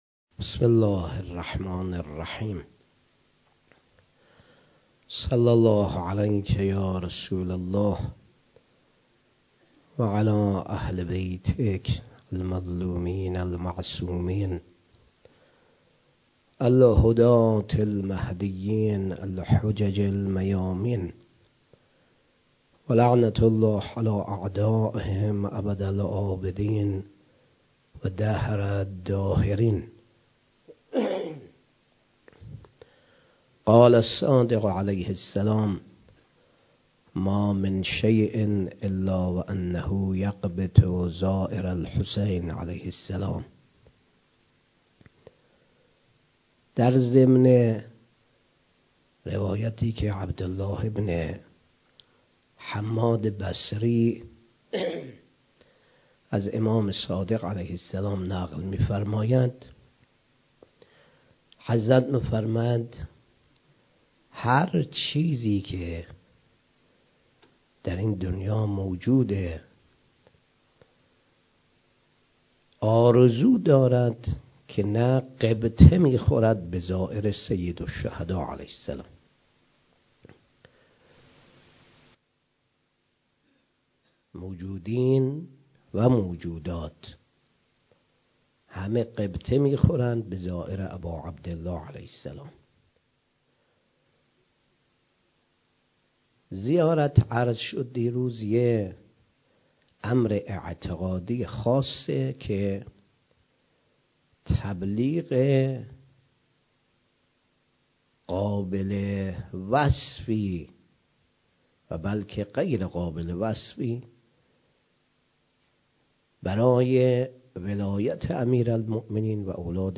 10 آبان 97 - دفتر وحید خراسانی - سخنرانی